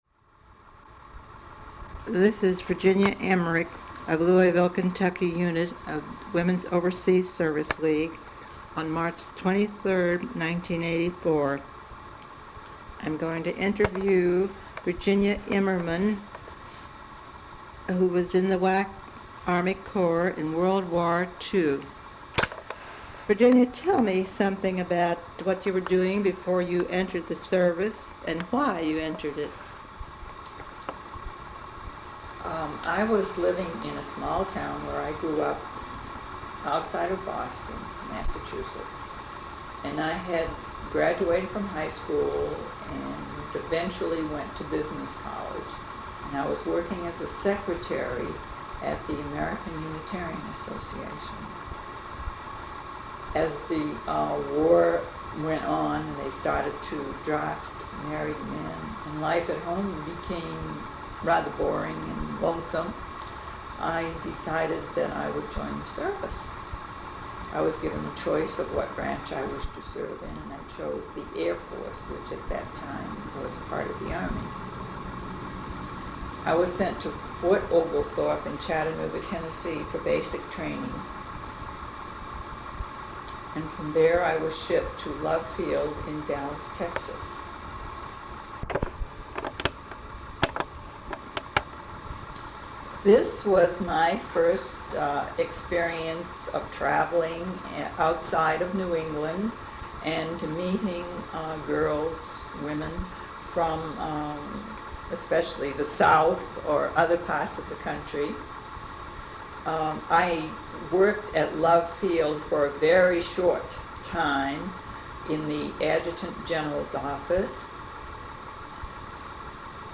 Women's Overseas Service League Oral History Project
Sound recordings Interviews